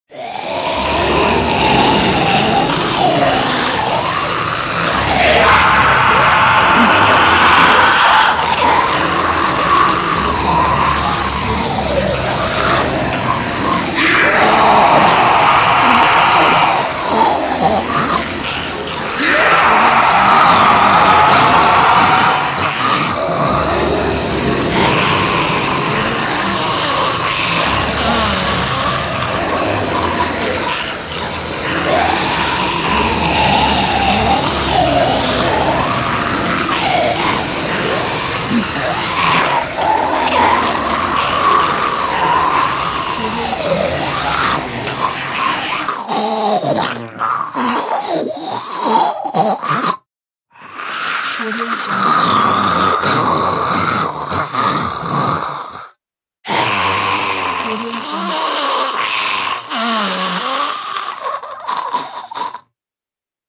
zombie.mp3